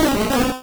Cri d'Akwakwak dans Pokémon Rouge et Bleu.